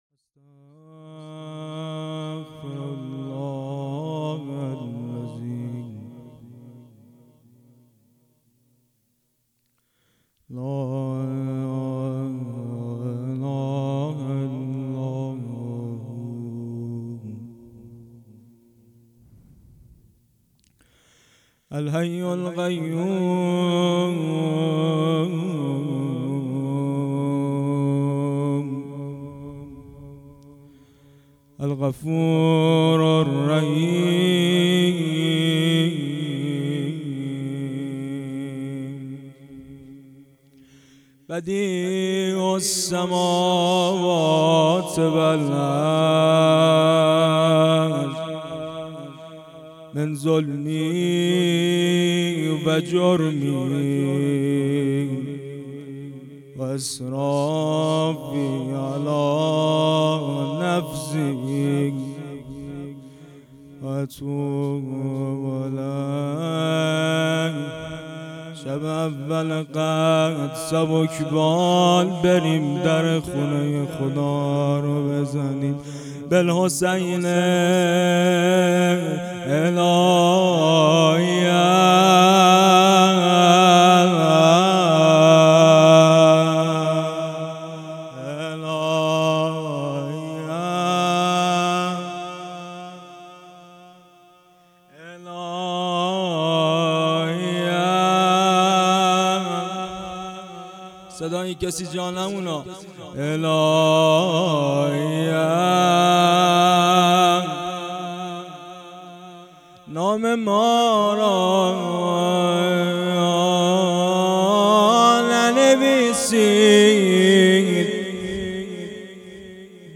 اجرا شده در حسنیه حضرت محسن هیئت رضیع الحسین سال 1400